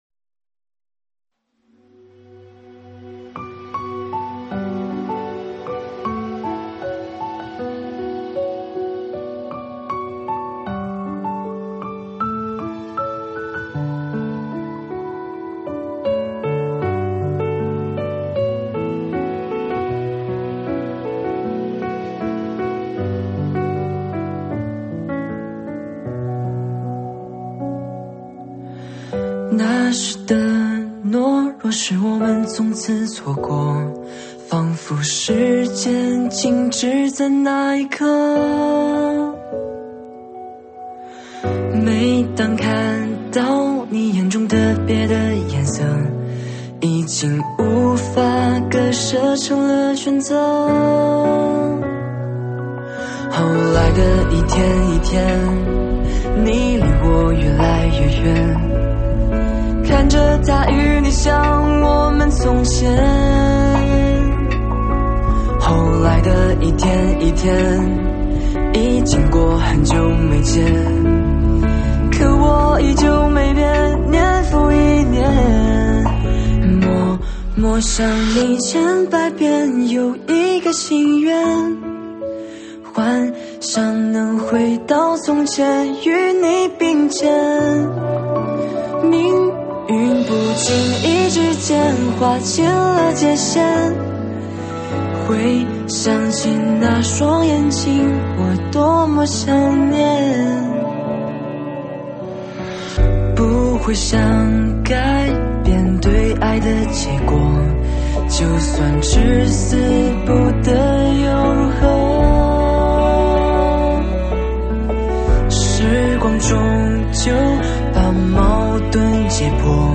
伤感情歌